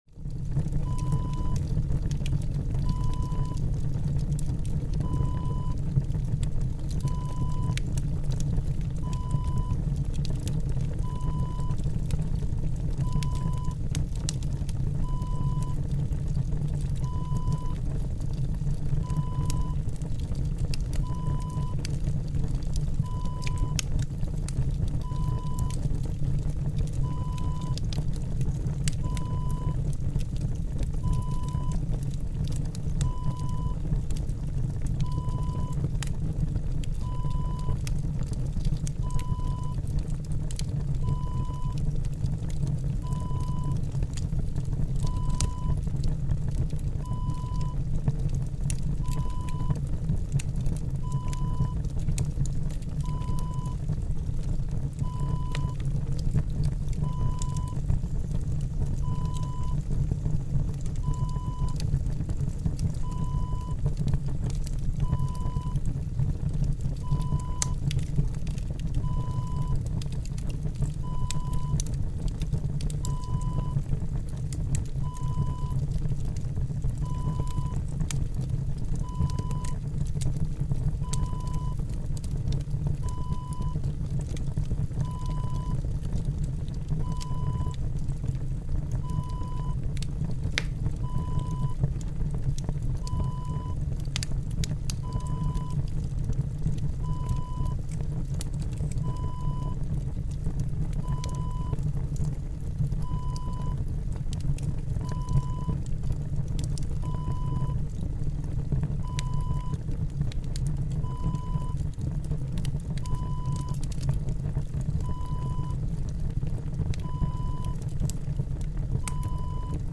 Wood Burning Stove Heater Wav Sound Effect #3
Description: The sound of wood burning in a wood burning stove heater (close up, light crackles)
Properties: 48.000 kHz 24-bit Stereo
A beep sound is embedded in the audio preview file but it is not present in the high resolution downloadable wav file.
Keywords: wood, burn, burning, burner, wood-burning, woodburning, stove, fire, heater, heat, heating, tent, camp, camping, army, military, furnace, boiler, radiator, cabin, crackles, crackling, flame, flames, flutter, fluttering, background, ambiance, ambience
wood-burning-stove-heater-preview-3.mp3